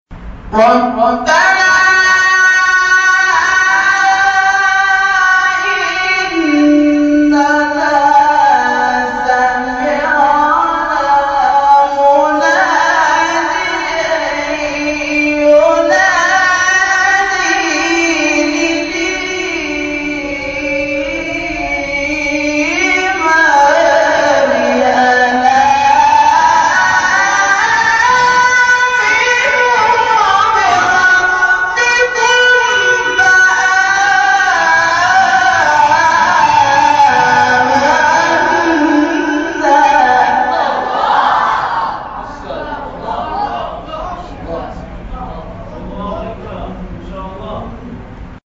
این فرازها به ترتیب در مقام‌های؛ نهاوند، عزام، بیات، حجاز و رست اجرا شده است.